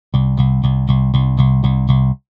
PICK縺ｯ繝斐ャ繧ｯ繧剃ｽｿ縺｣縺ｦ貍泌･上☆繧句･乗ｳ輔〒縺吶ょ鴨蠑ｷ縺輔→譏守椚縺ｪ繧｢繧ｿ繝繧ｯ髻ｳ縺檎音蠕ｴ縺ｧ縲∬ｿｫ蜉帙ｮ縺ゅｋ繧ｵ繧ｦ繝ｳ繝峨ｒ逕溘∩蜃ｺ縺励∪縺吶